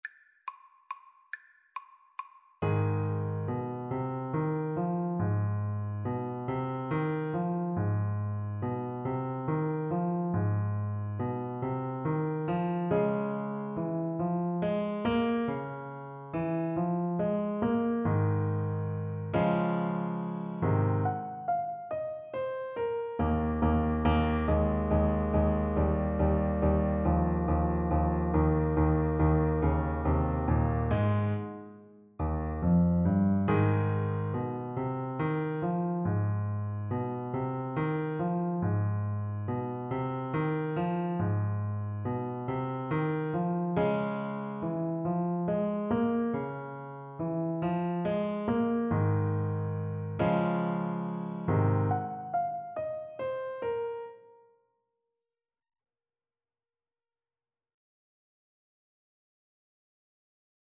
3/4 (View more 3/4 Music)
Arrangement for French Horn and Piano
= 140 Swinging
Jazz (View more Jazz French Horn Music)